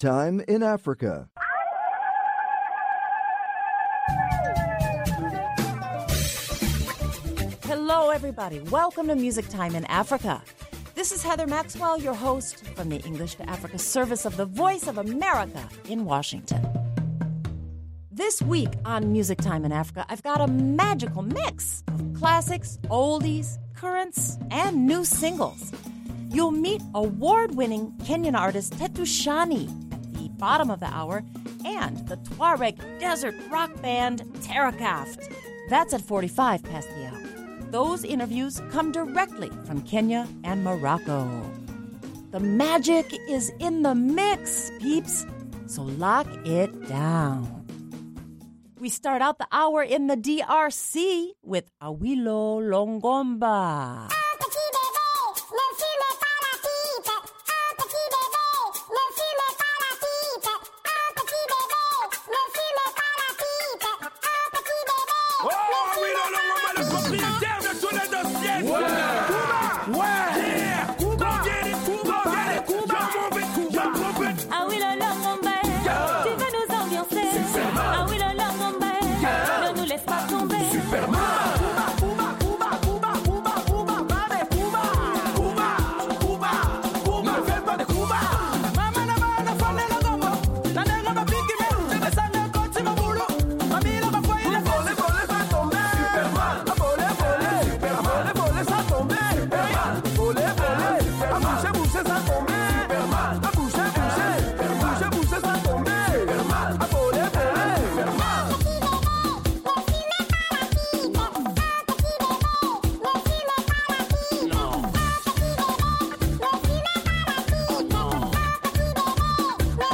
desert rock band